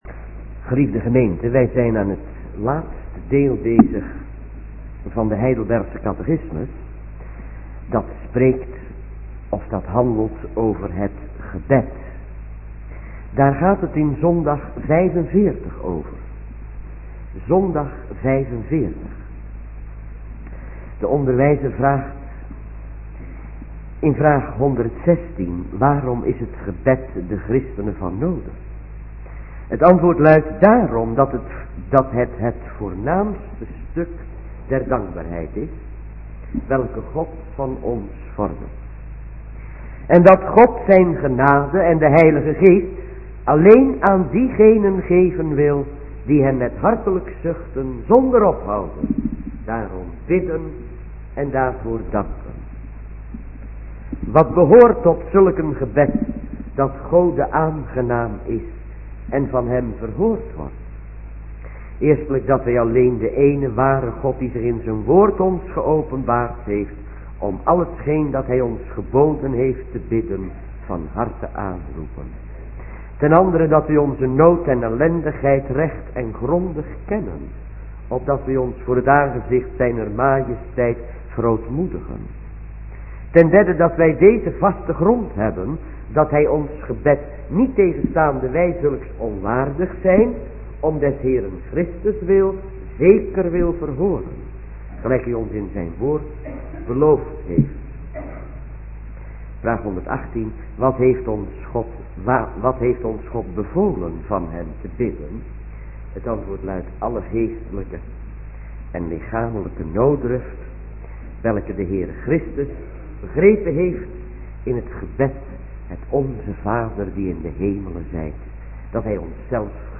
middagdienst -